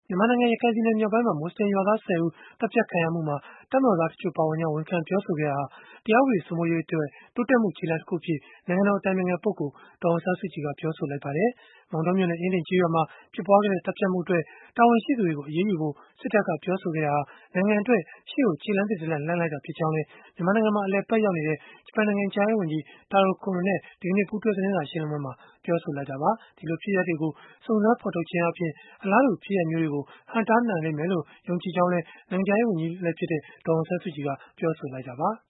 မောင်တောမြို့နယ် အင်းဒင်ကျေးရွာမှာ ဖြစ်ပွားခဲ့တဲ့ သတ်ဖြတ်မှုအတွက် တာဝန်ရှိသူတွေကို အရေးယူဖို့ စစ်တပ်က ပြောဆိုခဲ့တာဟာ နိုင်ငံအတွက် ရှေ့ကိုခြေလှမ်းသစ်တလှမ်း လှမ်းလိုက်တာဖြစ်ကြောင်း မြန်မာနိုင်ငံမှာ အလည်အပတ်ရောက်ရှိနေတဲ့ ဂျပန်နိုင်ငံခြားရေးဝန်ကြီး Taro Kono နဲ့ ဒီကနေ့ပူးတွဲ သတင်းစာရှင်းလင်းပွဲမှာ ပြောဆိုခဲ့တာပါ။